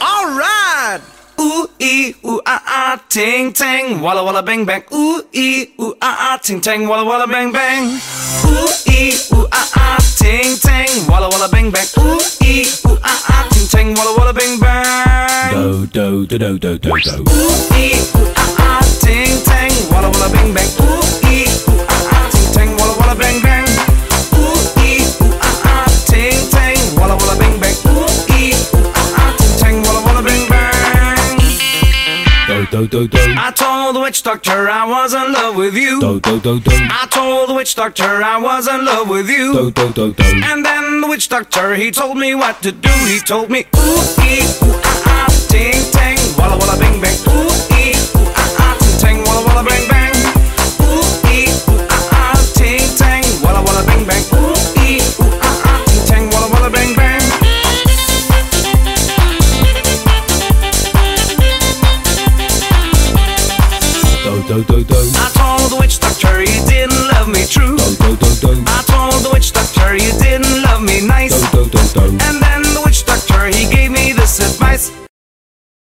BPM135--1
Audio QualityMusic Cut
- Music from custom cut